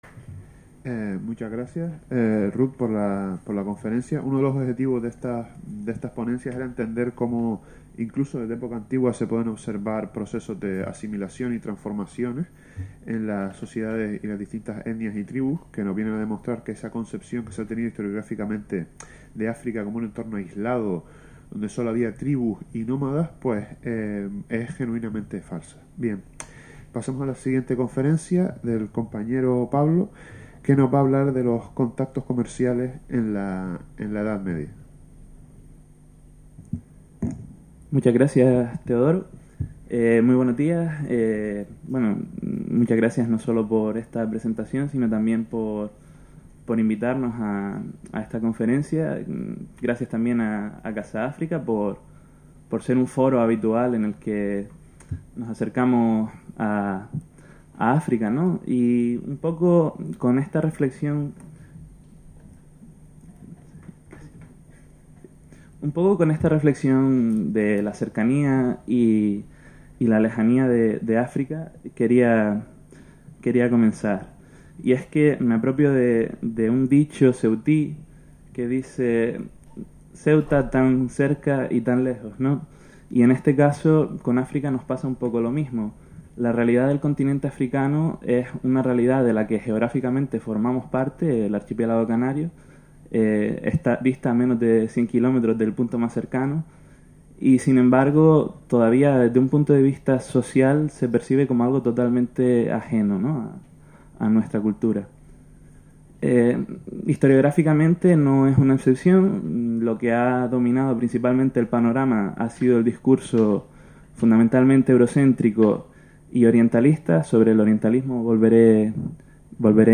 La Facultad de Geografía e Historia de la Universidad de Las Palmas de Gran Canaria organizó en Casa África este seminario de investigación compuesto por siete conferencias de media hora cada una que a lo largo del día analizaron la colonialidad en África, desde la Edad Antigua hasta el apartheid o los petroestados actuales.